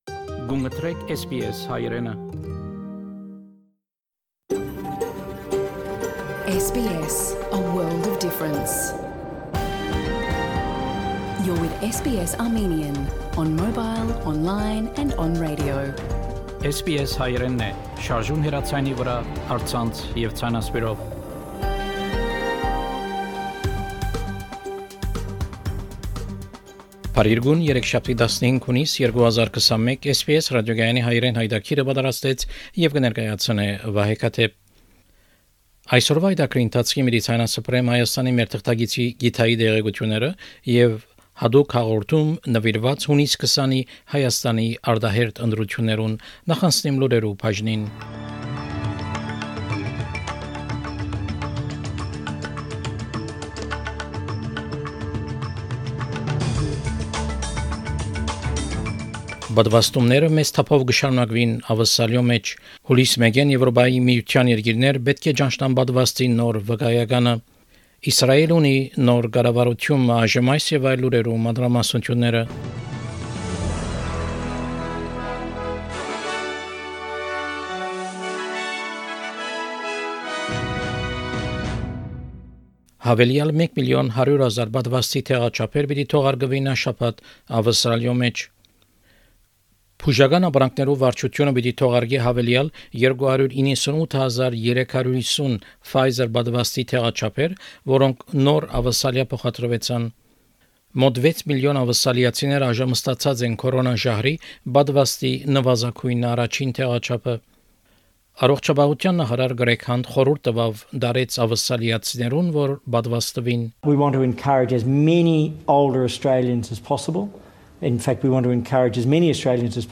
SBS Armenian news bulletin – 15 June 2021